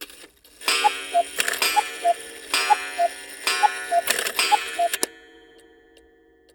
cuckoo-clock-05.wav